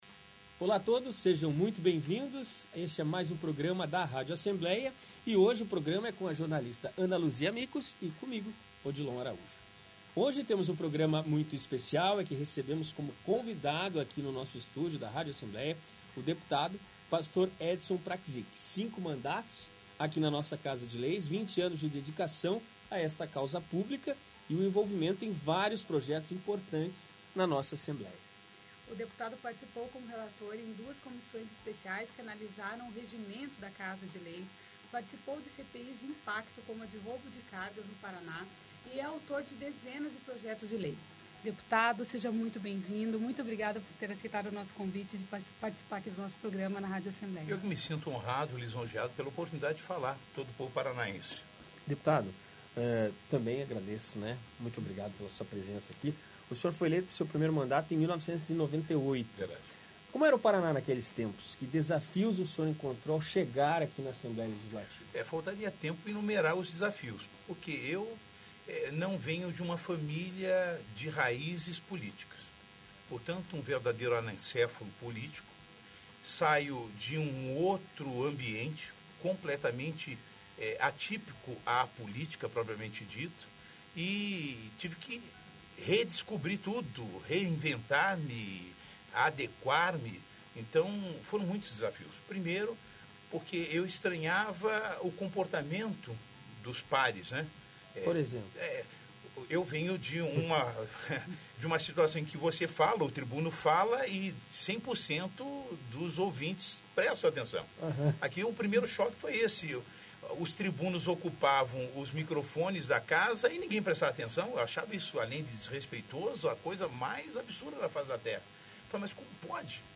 Ele não se candidatou à reeleição e deixa o cargo no fim de janeiro de 2019. Ouça a entrevista.